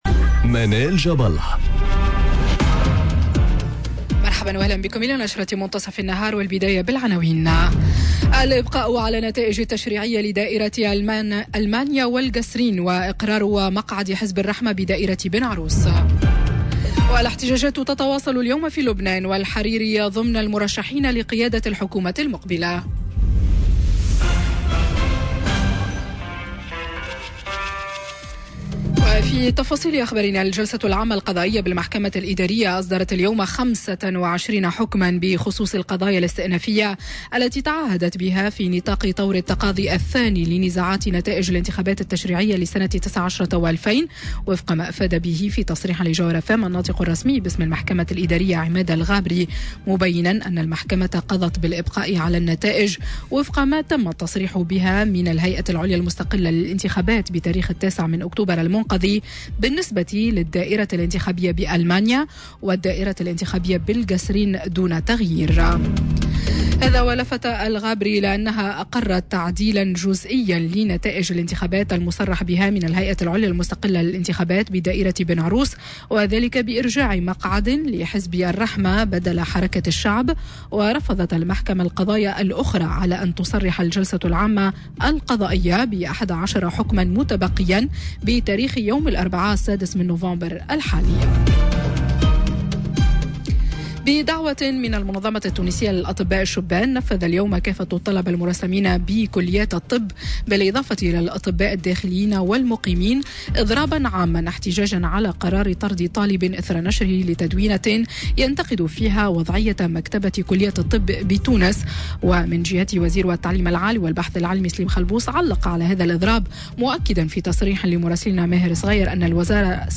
نشرة أخبار منتصف النهار ليوم الإثنين 04 نوفمبر 2019